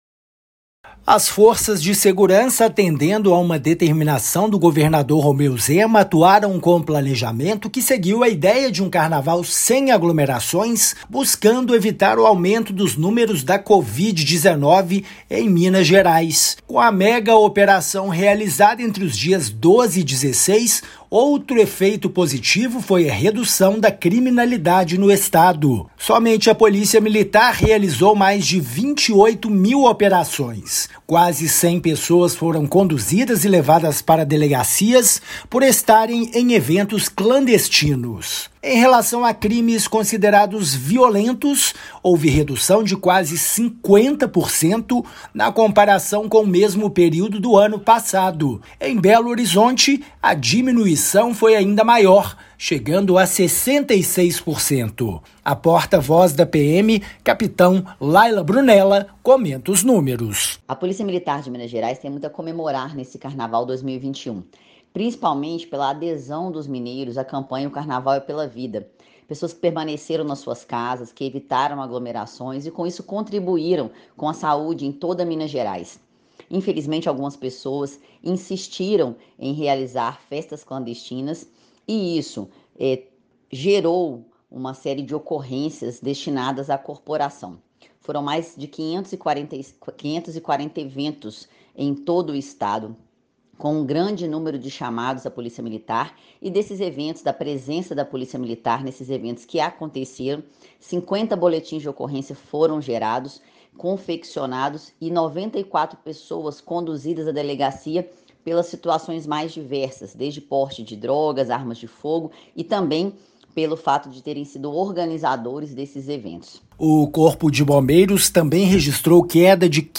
[RÁDIO] Megaoperação determinada pelo governador reduziu pela metade criminalidade no estado durante o Carnaval
MATÉRIA_RÁDIO_BALANÇO_MEGAOPERAÇÃO_CARNAVAL.mp3